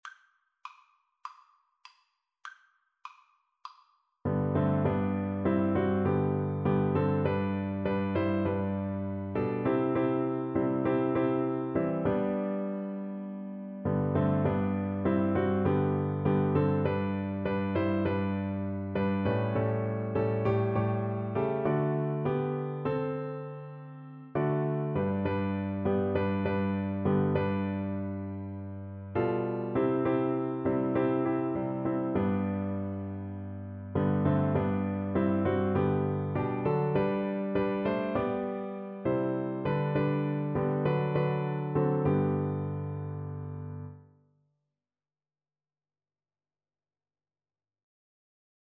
G major (Sounding Pitch) (View more G major Music for Flute )
4/4 (View more 4/4 Music)
jesus_came_FL_kar1.mp3